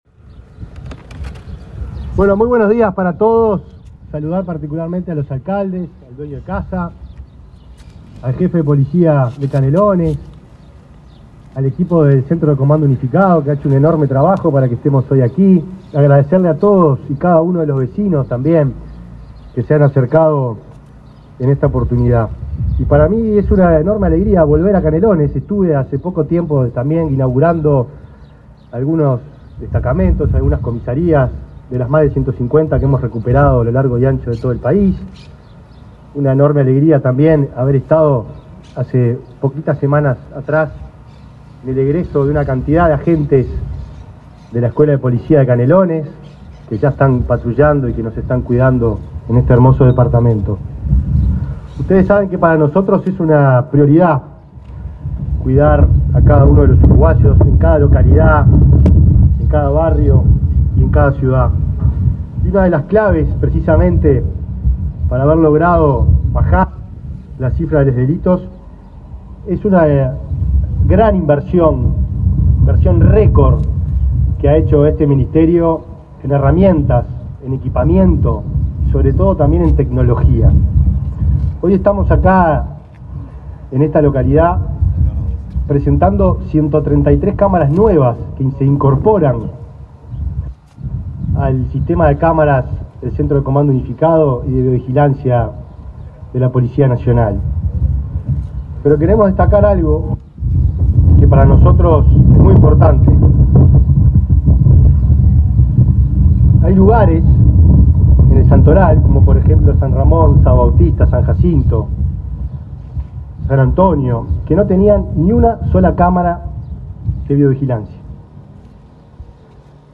Palabras del ministro del Interior, Nicolás Martinelli
El ministro del Interior, Nicolás Martinelli, participó, este jueves 19, en la inauguración de cámaras de videovigilancia en la localidad de San Ramón